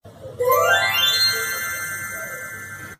efek_benar.ogg